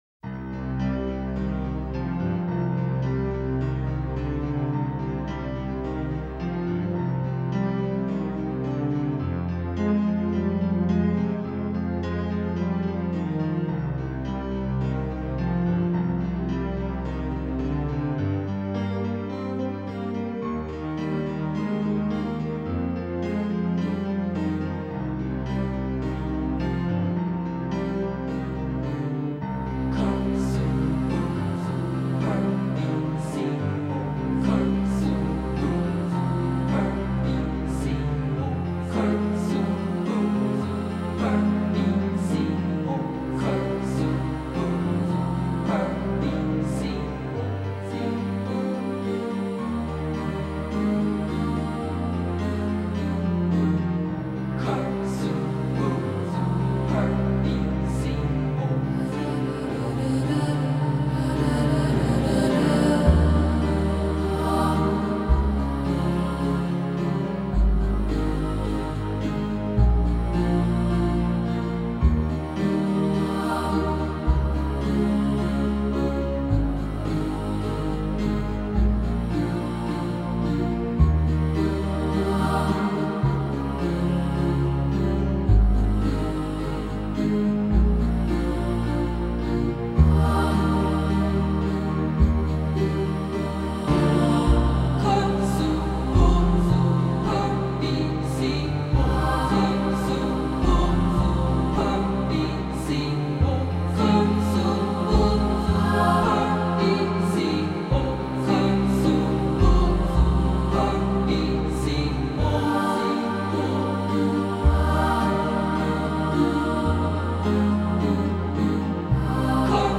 Genre: New Age
Recorded at Orinoco Studios